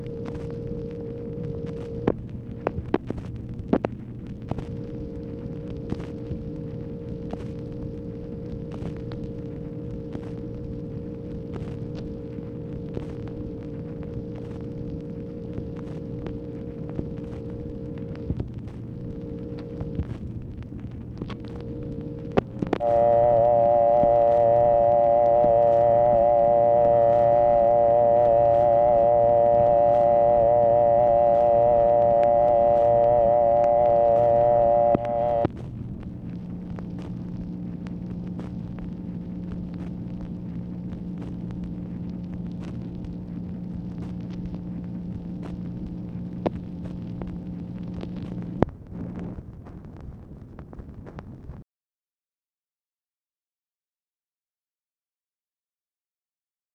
MACHINE NOISE, November 18, 1964
Secret White House Tapes | Lyndon B. Johnson Presidency